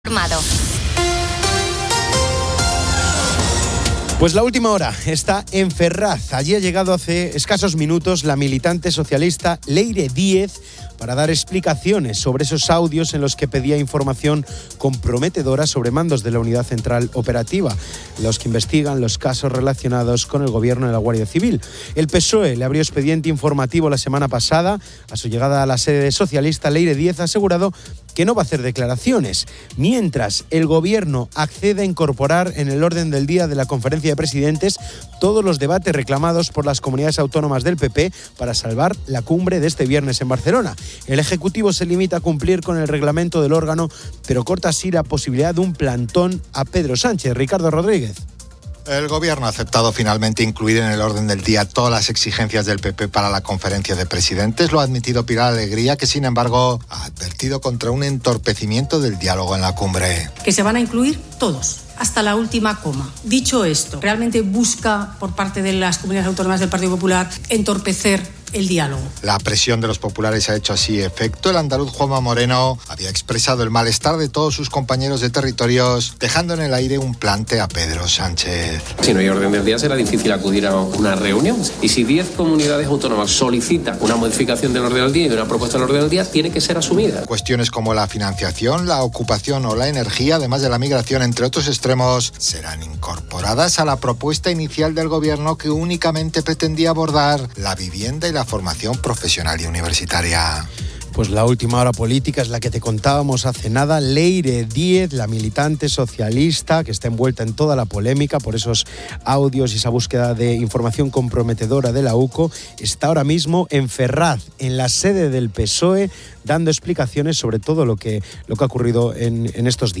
También habla con estudiantes que acaban de examinarse en el primer día de la Prueba de Acceso a la Universidad (PAU).